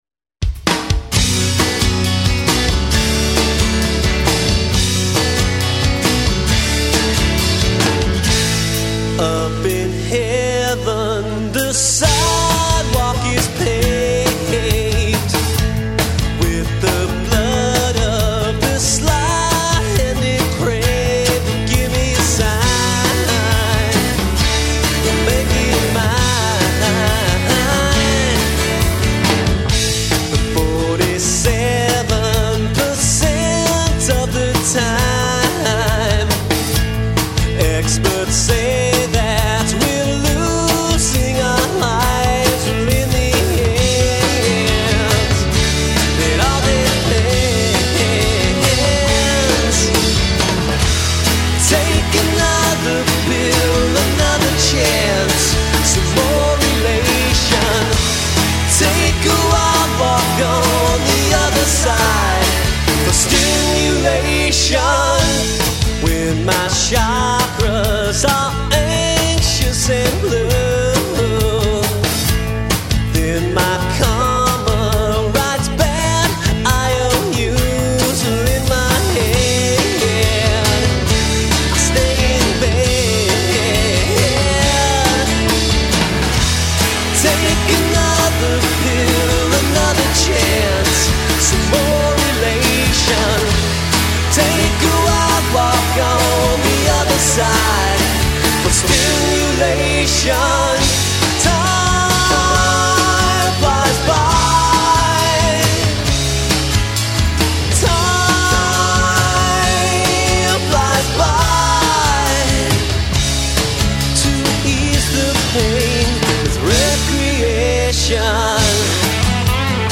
Melodic, high-energy, guitar-driven rock from St. Louis, MO
Lead vocals, guitar
Bass guitar, vocals